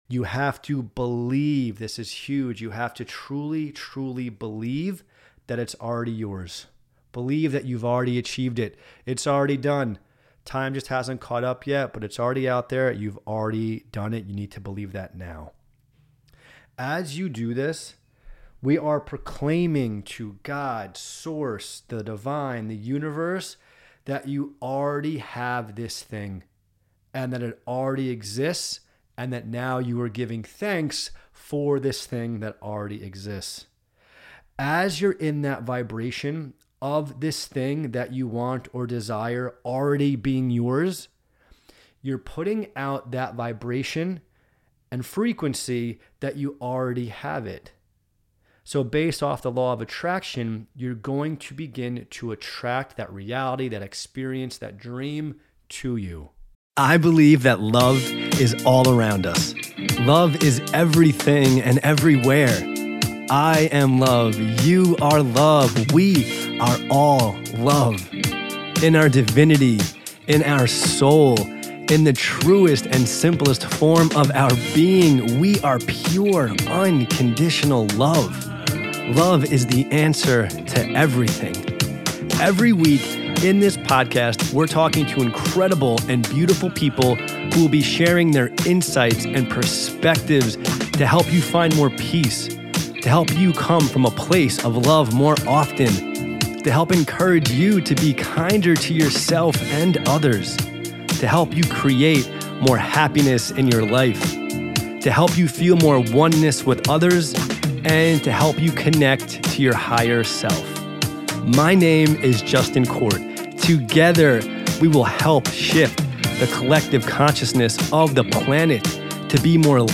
keynote speech